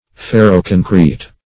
Ferro-concrete \Fer"ro-con"crete\, n. (Arch. & Engin.)